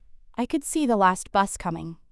「ラスバス」と聞こえたんじゃないでしょうか？
last bus」の t が脱落して、「ラスバス」になってるんですね。